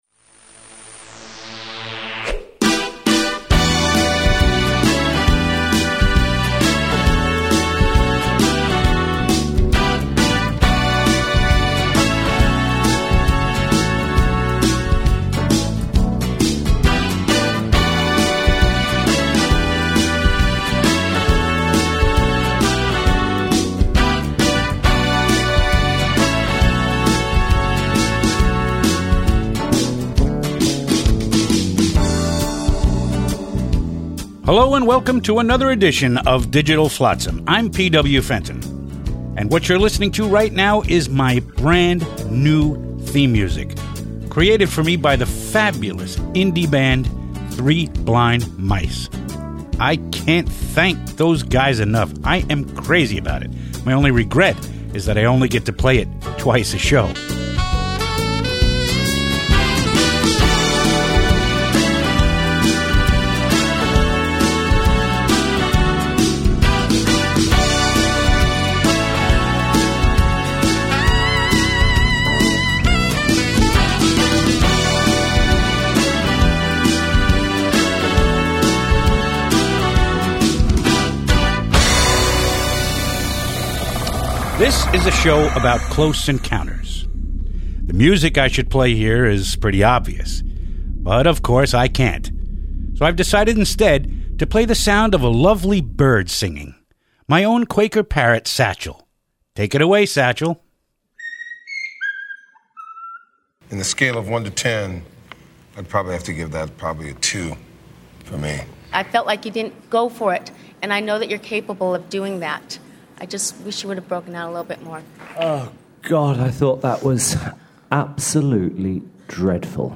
We are proud to offer these great spoken word pieces again.